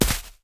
Footsteps
snow.wav